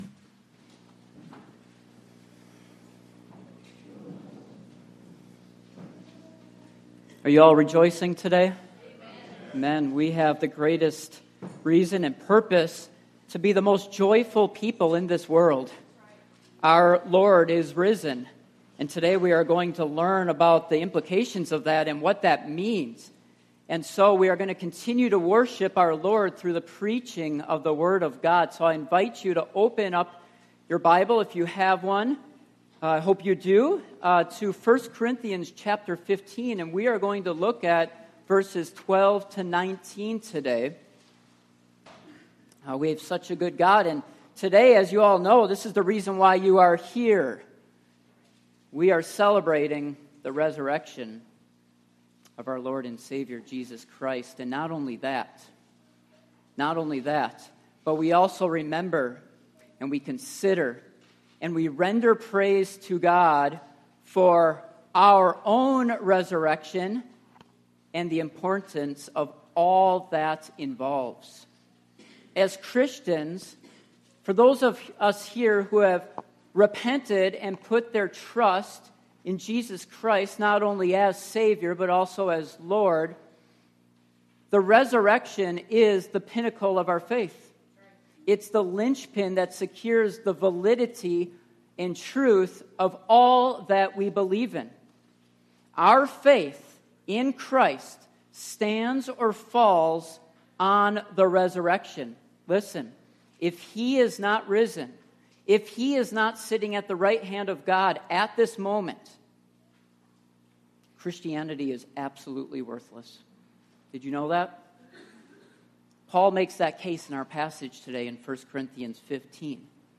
Passage: 1 Corinthians 15:12-19 Service Type: Morning Worship